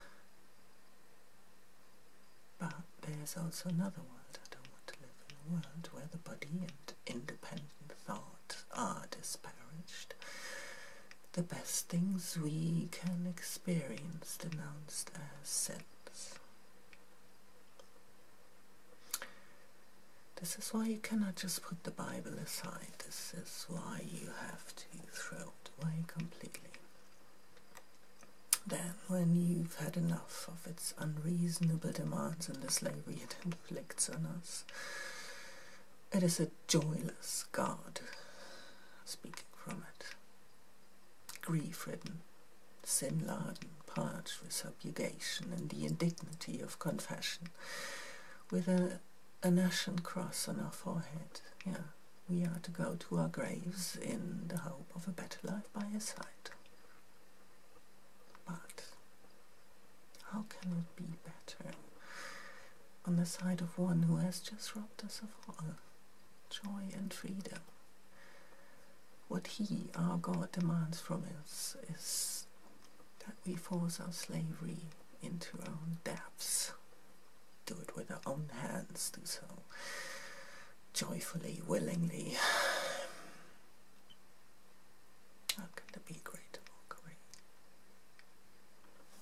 Graduation Speech Amadeu de Prado from "Nighttrain to Lisbon".
Not exactly sure if me speaking them, gives them the desired impact...but I so wanted to say them.